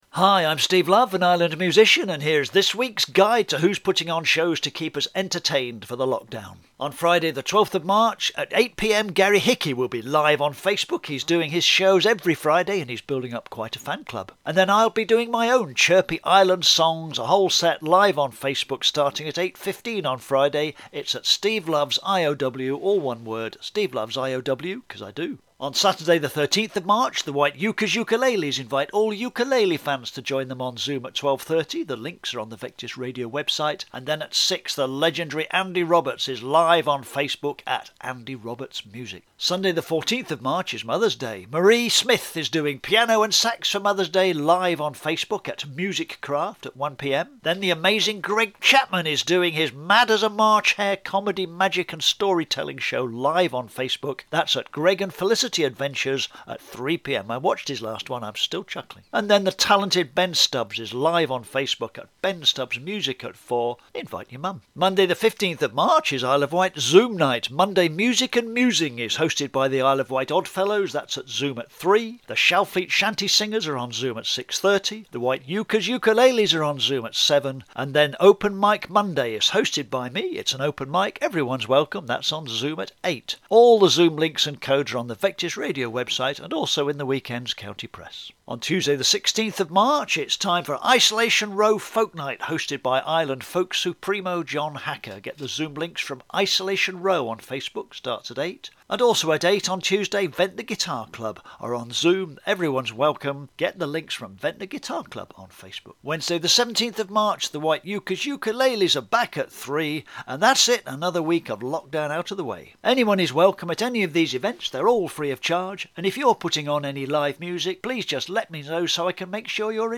Live music from 12 March 2021.